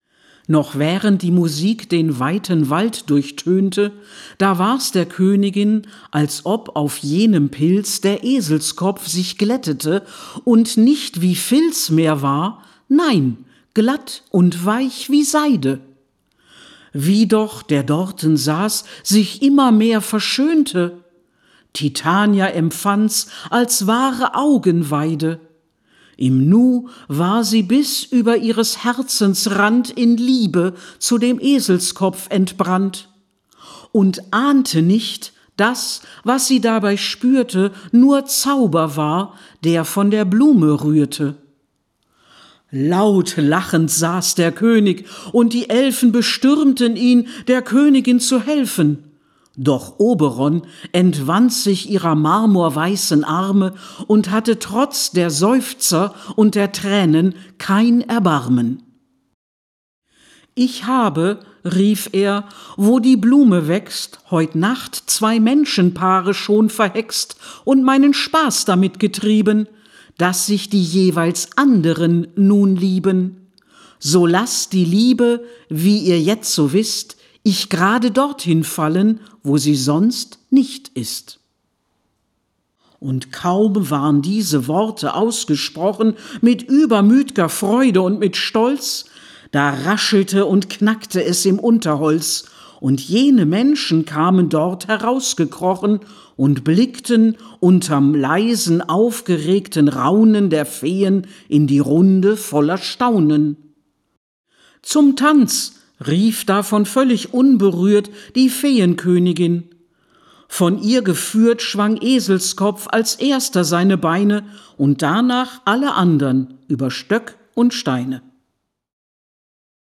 „The Fairy Queen“ von Purcell, als Blockflötenquartett bearbeitet, lag plötzlich in meinen Händen.
an einem recht heißen Samstag unsere Blockflötenquartette für das Klassenspiel und auch die Fairy Queen professionell aufnehmen zu lassen.